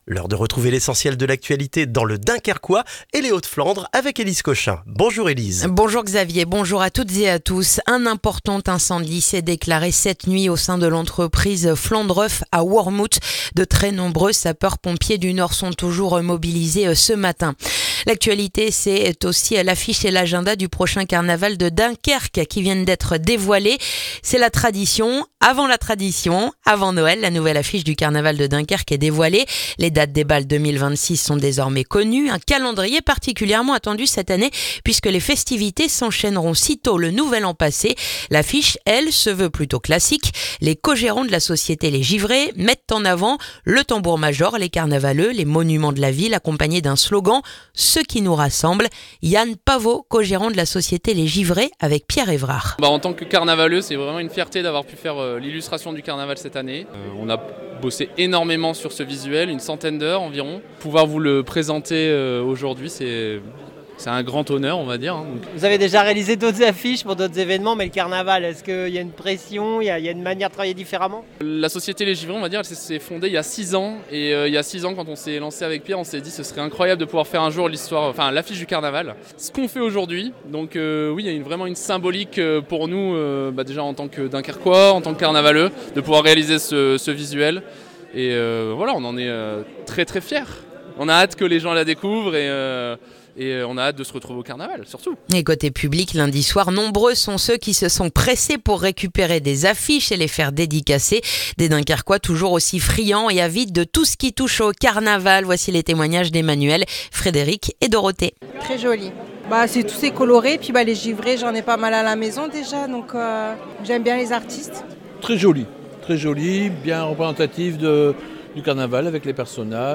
Le journal du mercredi 17 décembre dans le dunkerquois